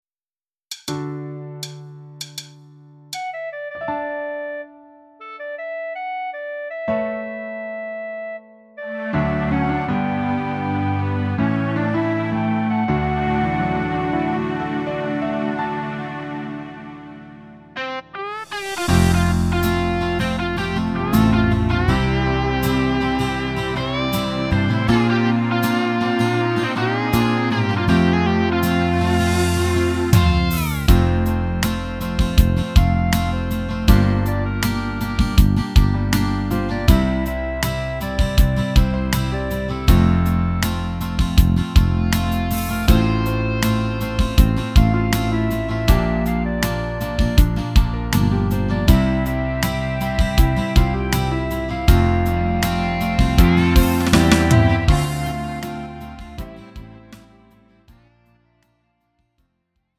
음정 -1키 3:50
장르 가요 구분 Lite MR